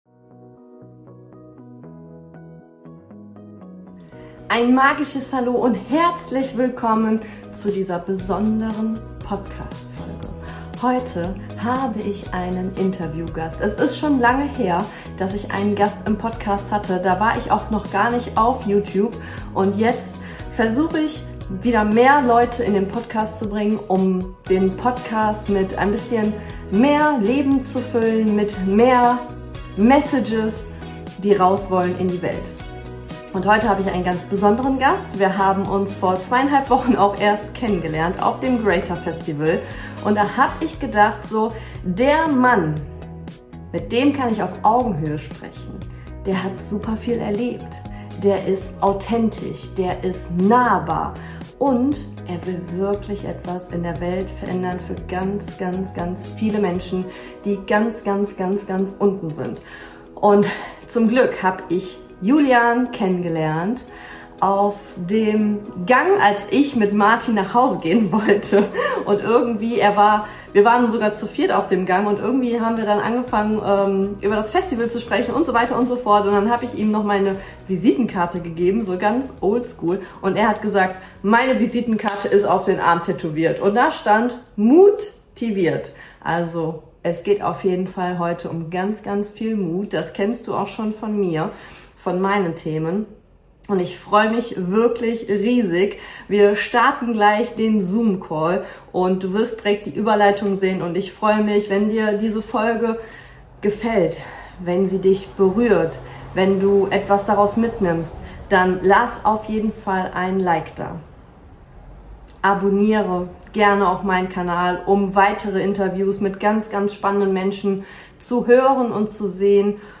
Zwei Coaches im ehrlichen Austausch über mentale Stärke und Lebenswege